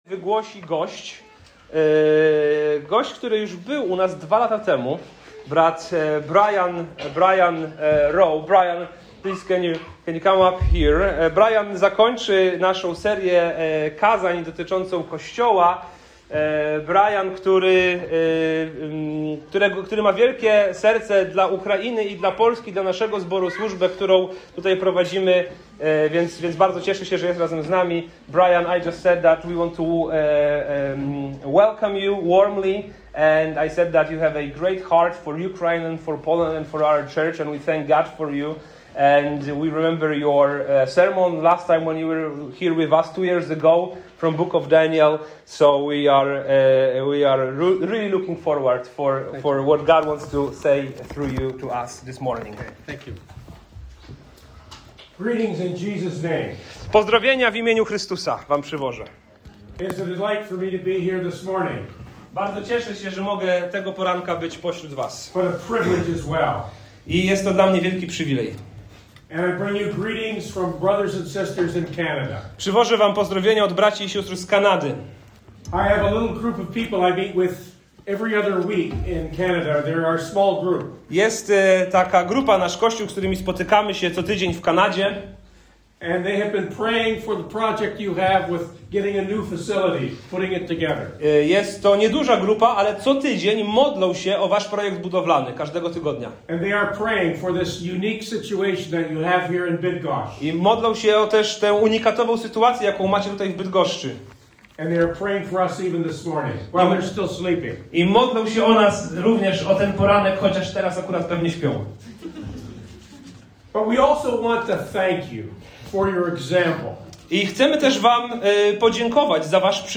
Kazanie zachęcające do służby w Kościele